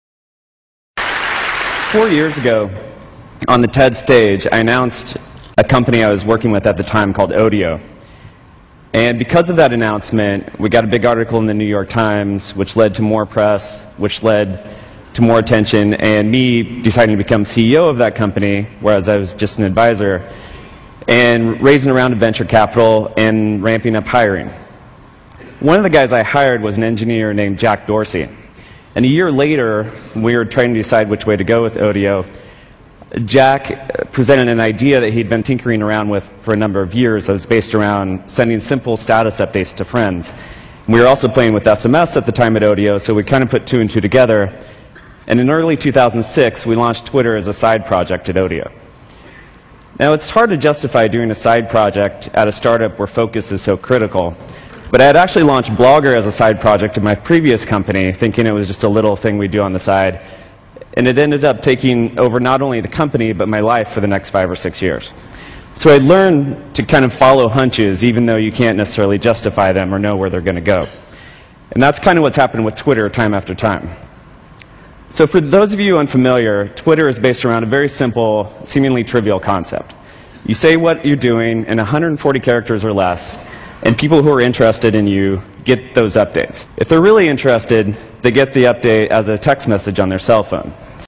财富精英励志演讲66：分享生活点滴(1) 听力文件下载—在线英语听力室